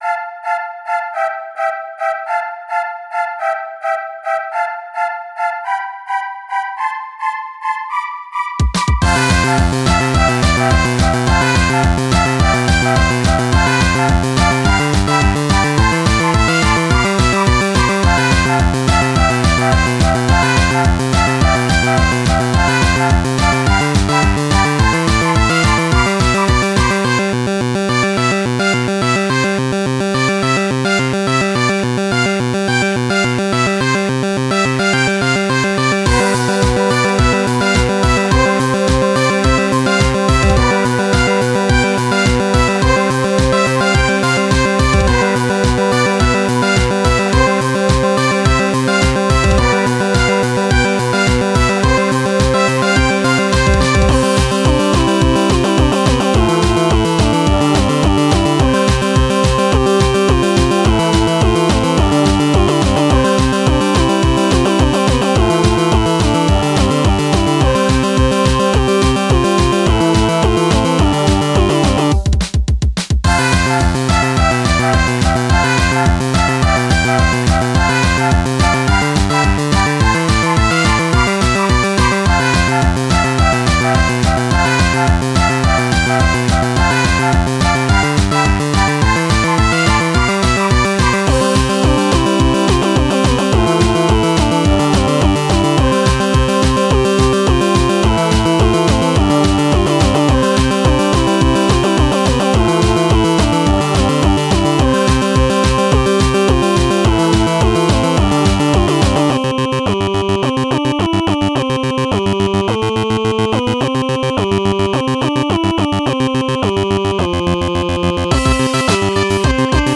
8bit music gamemusic